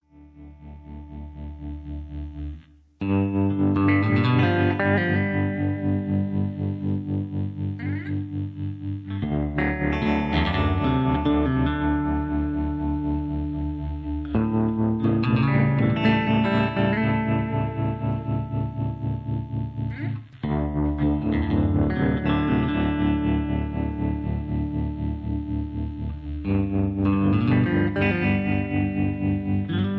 backing tracks
karaoke
rock and roll, r and b